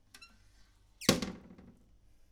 Toilet Seat Taking Down Sound
household
Toilet Seat Taking Down